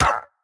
minecraft-assets/assets/minecraft/sounds/mob/parrot/hurt2.ogg at 1ecf9ab3bd63201dfbcd6d3d088c8c60668dadf4